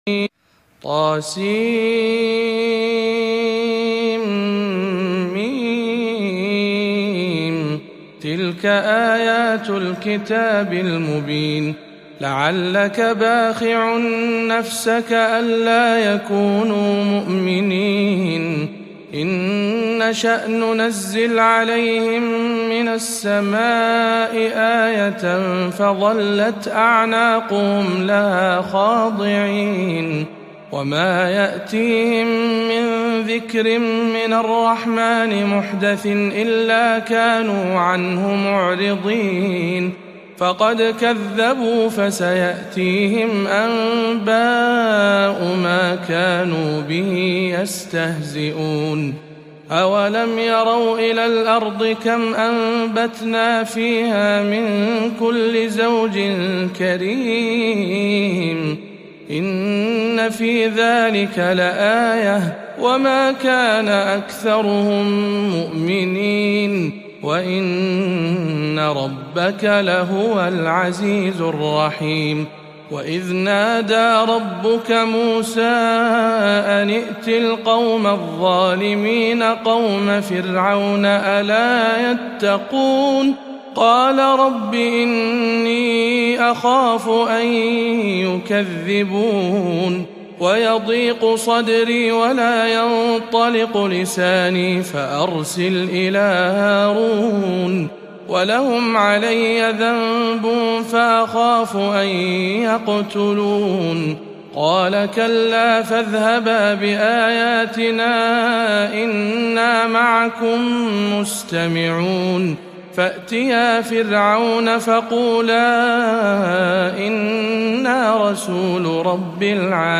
سورة الشعراء بمسجد المحمدية الغربية بالرياض - رمضان 1438 هـ